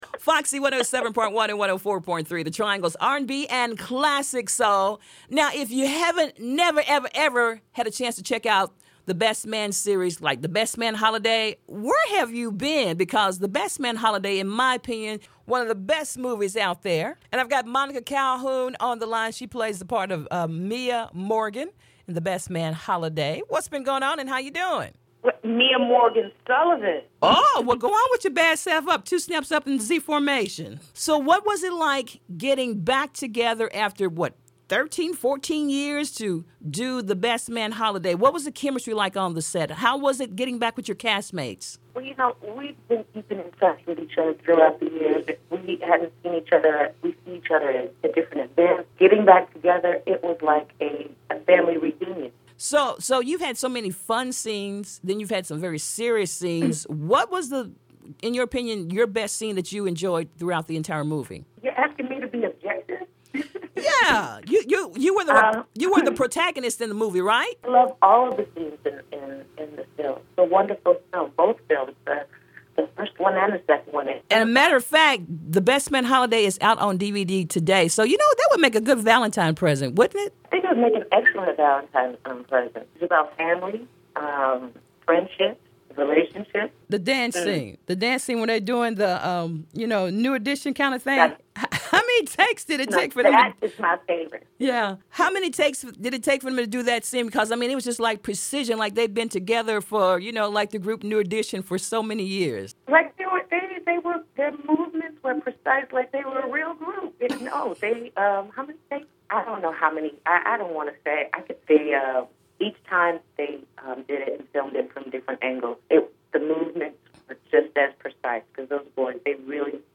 I had an opportunity to talk with actress Monica Calhoun about the chemistry on the set.
monica-calhoun-interview.mp3